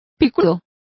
Also find out how picuda is pronounced correctly.